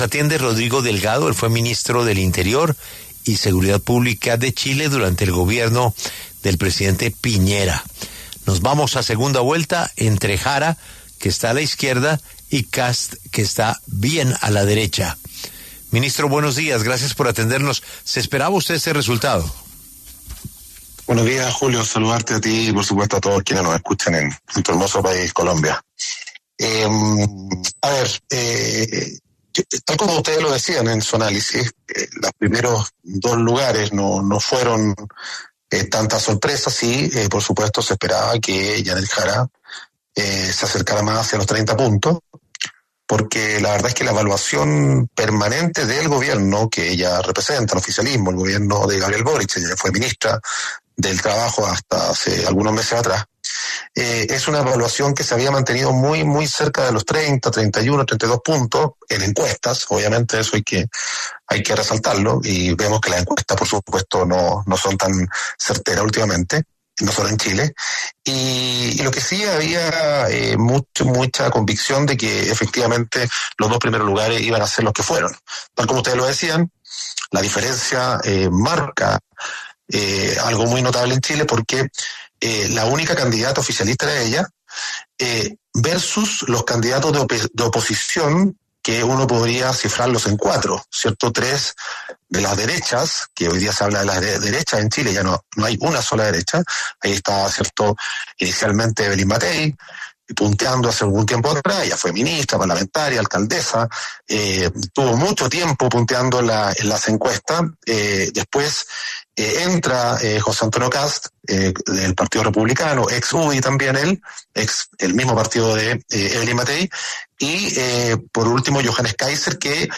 El exministro de Interior chileno Rodrigo Delgado habló en La W para ofrecer su panorama de las elecciones presidenciales en ese país.
Por este motivo, en los micrófonos de La W, con Julio Sánchez Cristo, habló el exministro de Interior chileno Rodrigo Delgado, quien analizó la primera vuelta de las elecciones y el panorama de la segunda.